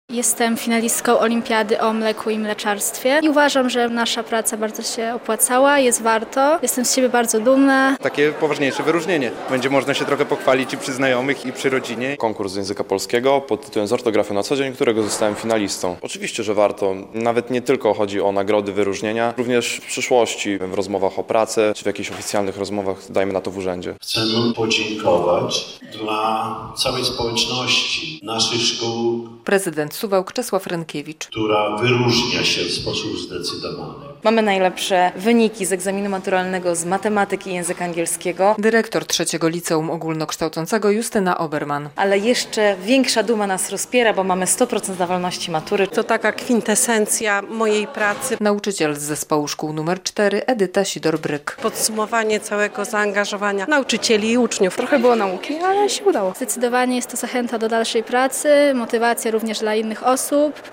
W Suwałkach nagrodzono uczniów za świetne wyniki w nauce i sporcie. W czwartek (25.09), podczas uroczystej gali w Suwalskim Ośrodku Kultury, wręczono stypendia i nagrody Prezydenta Miasta Suwałk.